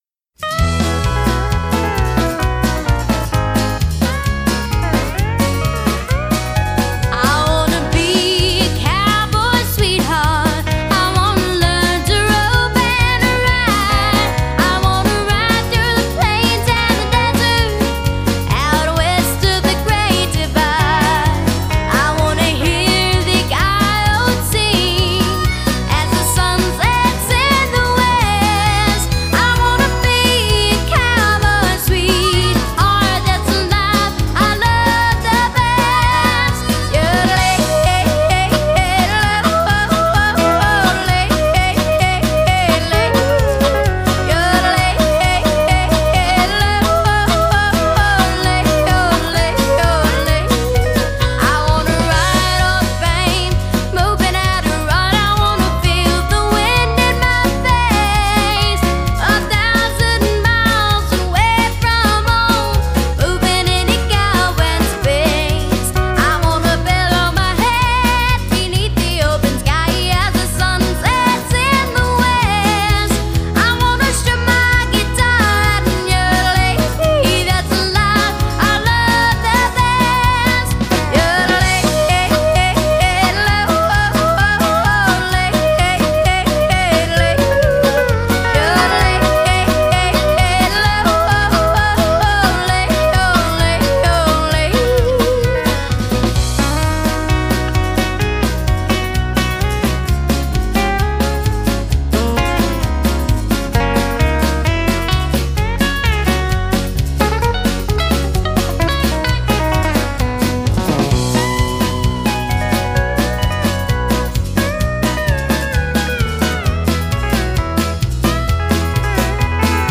音樂類型：乡村、流行